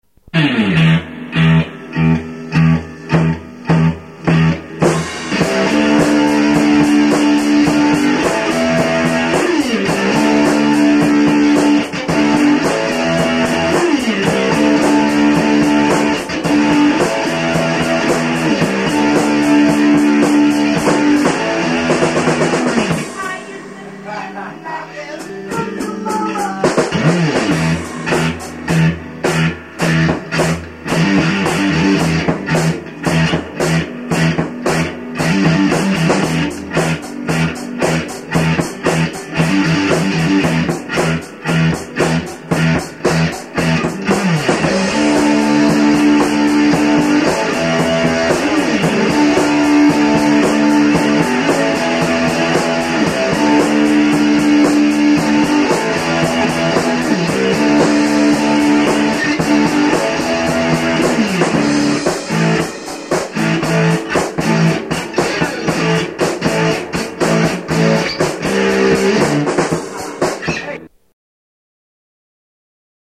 Dig the nice breakdown - it was completely unplanned.